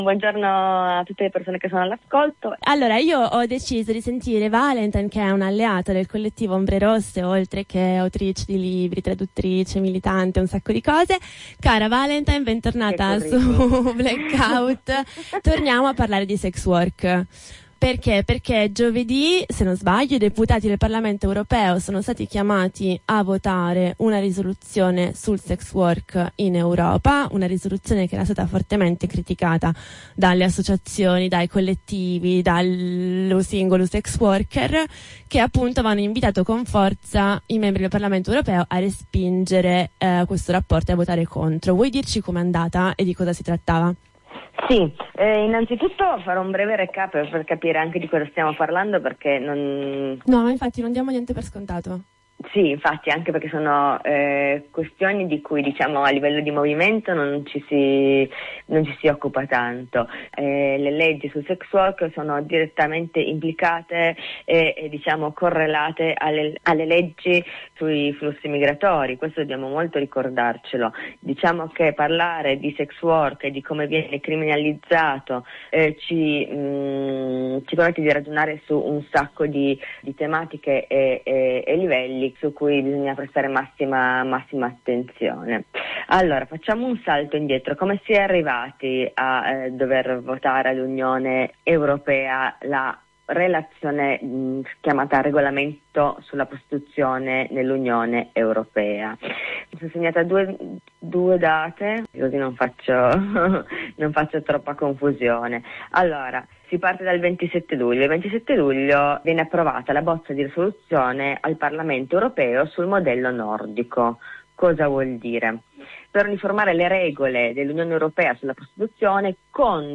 Nella chiacchierata
ascolta la diretta: